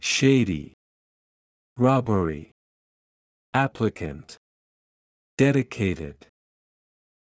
音声を再生し、強勢のある母音（＝大きな赤文字）を意識しながら次の手順で練習しましょう。